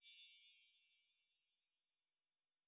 Magic Chimes 03.wav